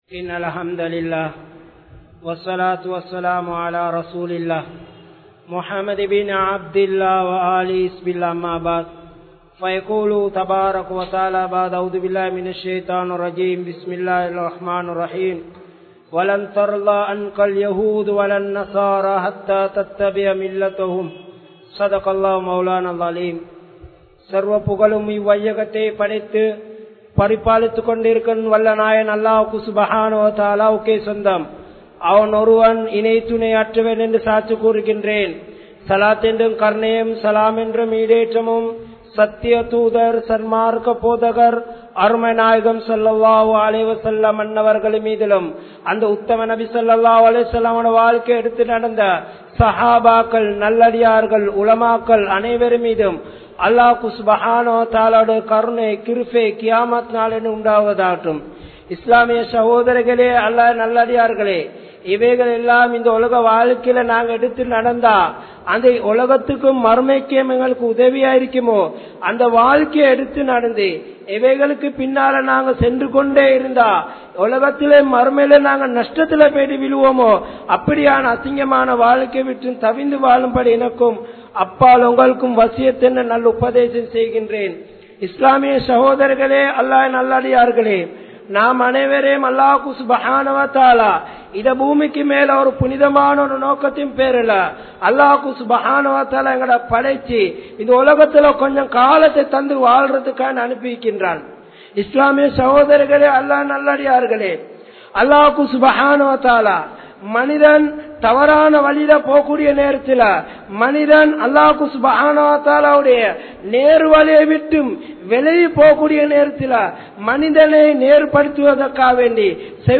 Nabi Vali (நபி வழி) | Audio Bayans | All Ceylon Muslim Youth Community | Addalaichenai